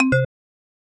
Button_scale_1.wav